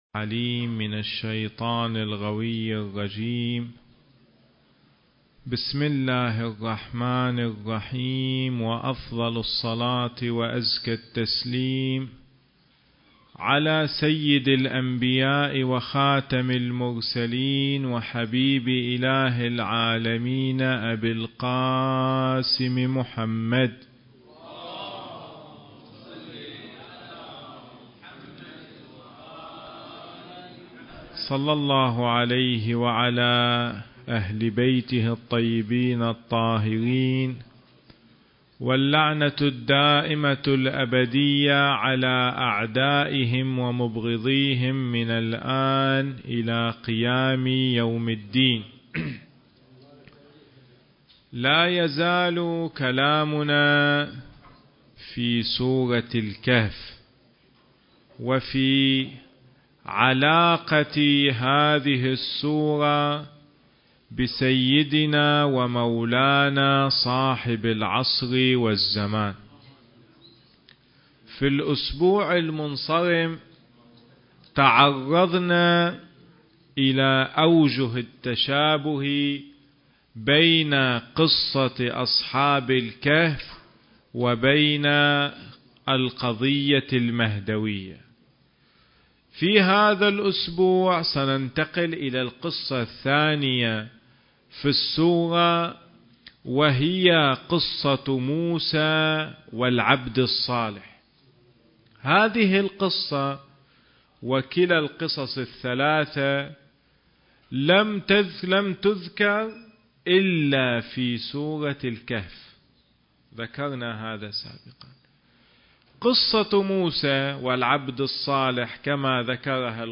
سلسلة: الإمام الحجة (عجّل الله فرجه) في سورة الكهف (3) حديث الجمعة التاريخ: 2015